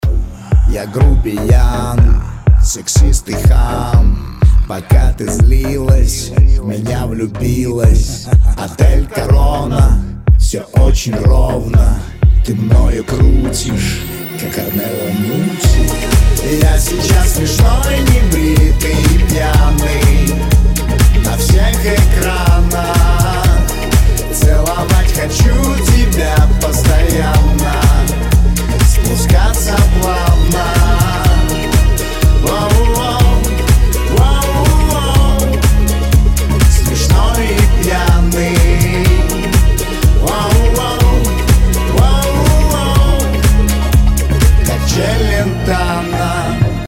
• Качество: 256, Stereo